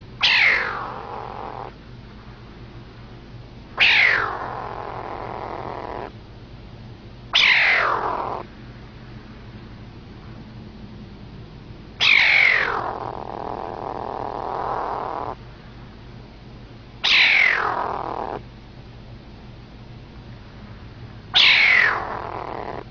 Злобный звук крошечного каракала